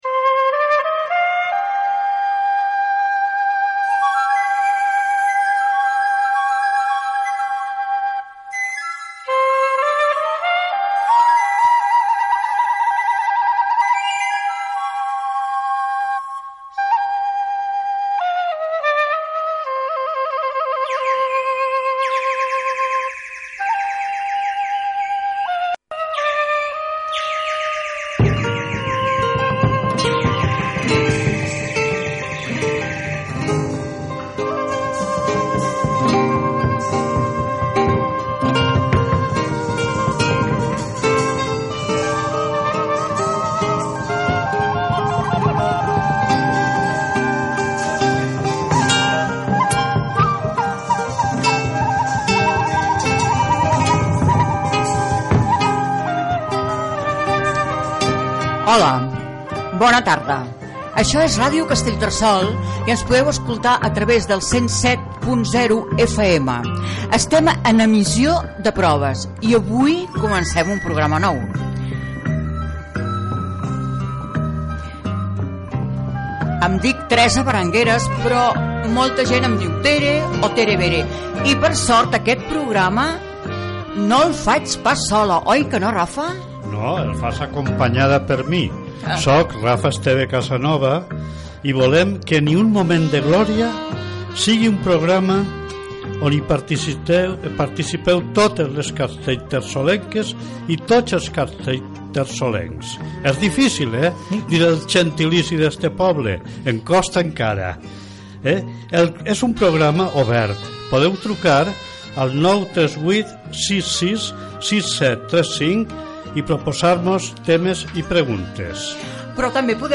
Sintonia, identificació de l'emissora en període de proves, presentació de la primera edició del programa, comentari sobre l'empresonament dels polítics Jordi Turull, Josep Rull, Carme Forcadell, Raul Romeva i Dolors Bassa
Entreteniment